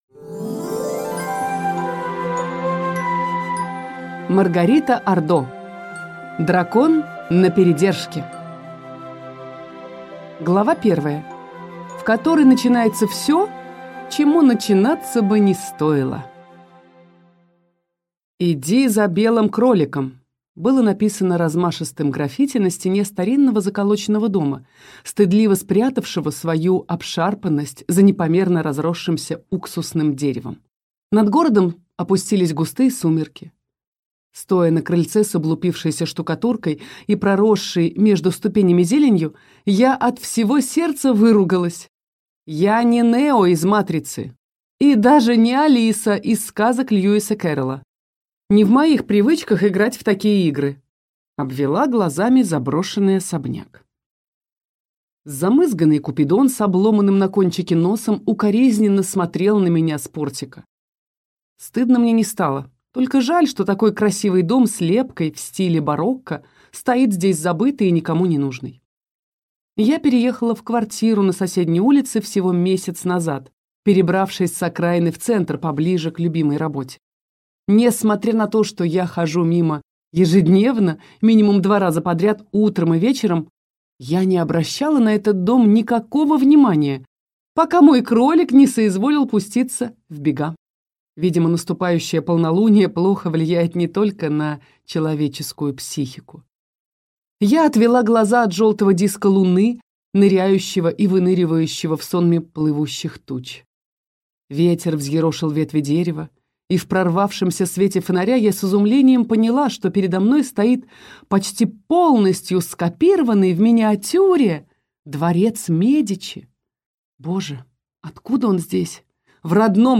Аудиокнига Дракон на передержке | Библиотека аудиокниг
Прослушать и бесплатно скачать фрагмент аудиокниги